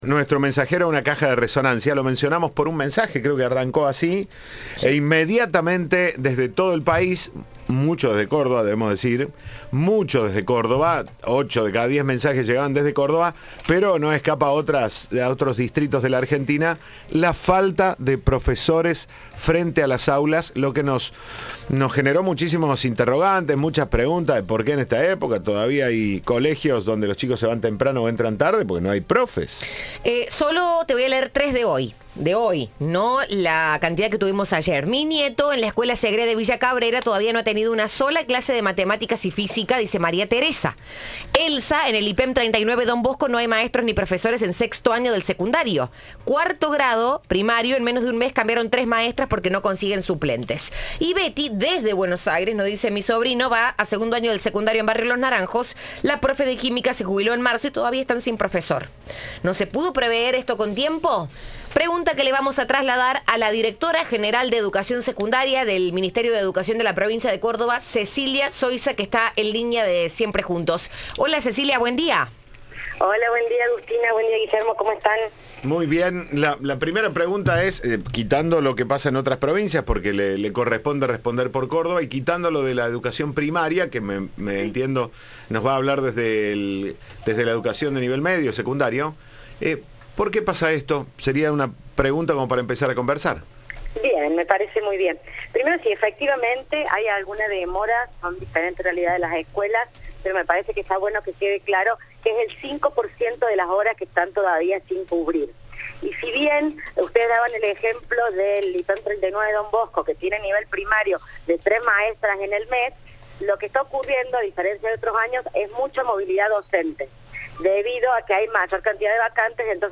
La directora de Educación Secundaria del Ministerio de Educación de Córdoba, Cecilia Soisa, habló en Cadena 3 sobre la problemática y explicó cómo funciona el sistema de convocatoria de profesores.